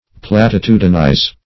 \Plat`i*tu"di*nize\